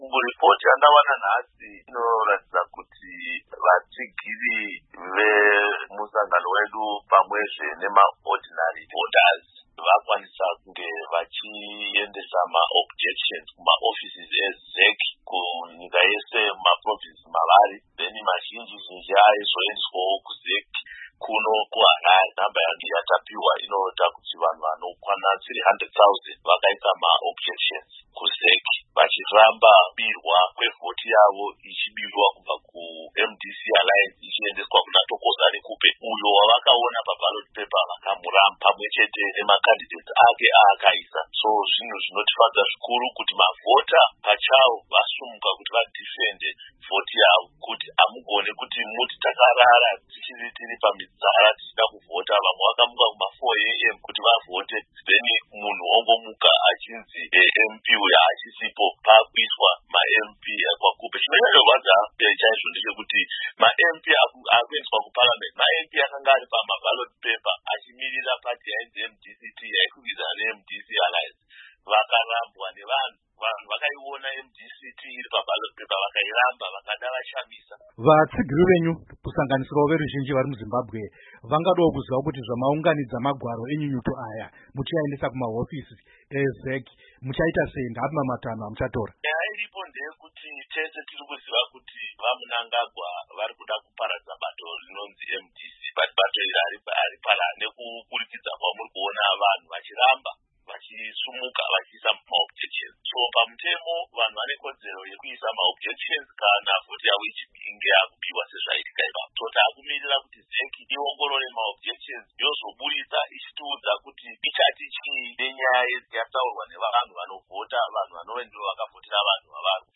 Hurukuro naVaChalton Hwende